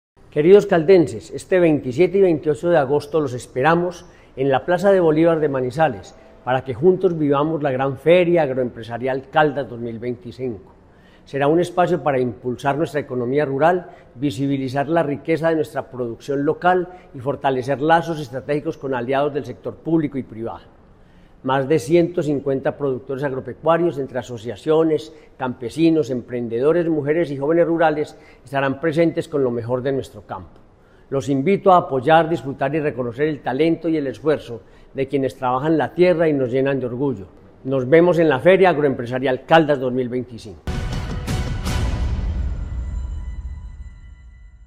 Henry Gutiérrez Ángel, gobernador de Caldas.